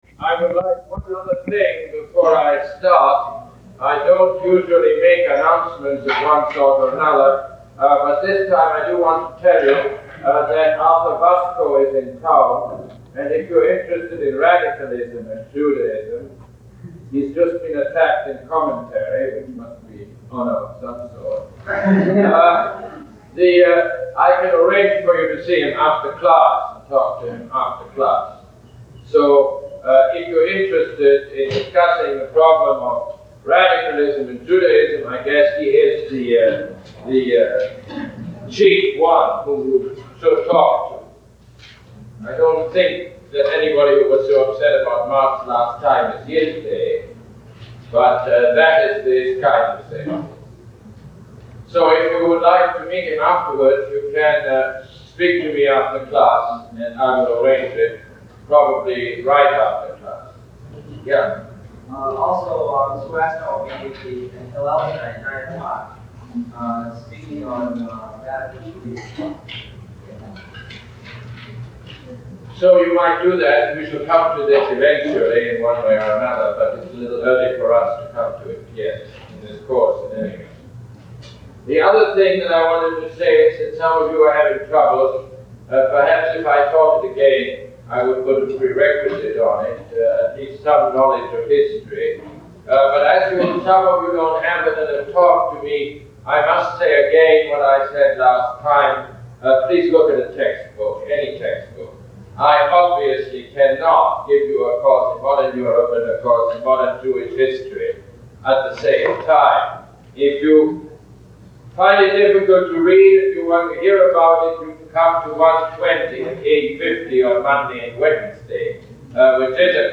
Lecture #12 - March 12, 1971